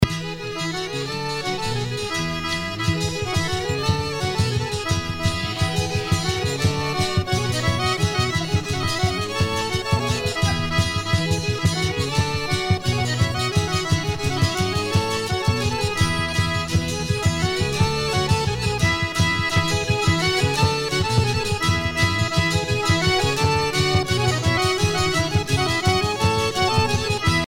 danse : plinn
Pièce musicale éditée